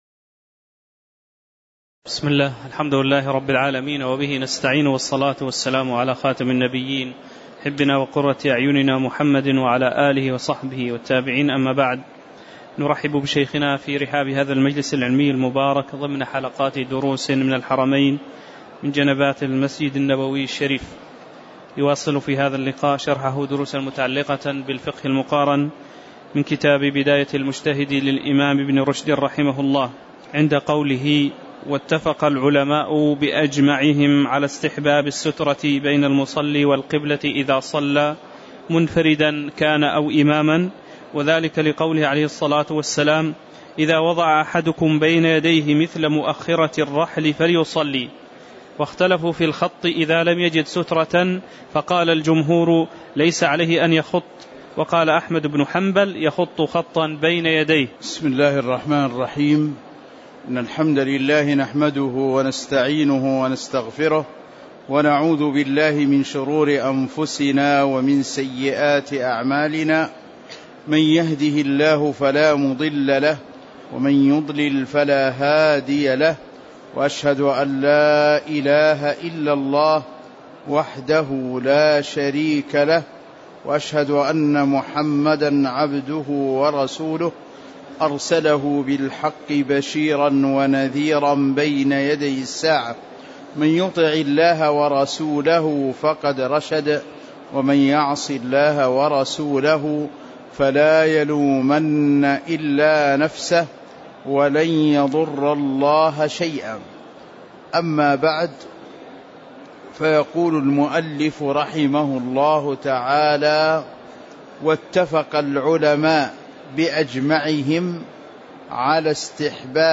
تاريخ النشر ١٣ ربيع الأول ١٤٤١ هـ المكان: المسجد النبوي الشيخ